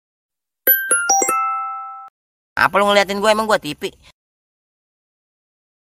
Genre: Nada notifikasi
nada-notifikasi-wa-apa-lu-ngeliatin-gue-emang-gue-tv.mp3